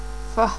(popular canario)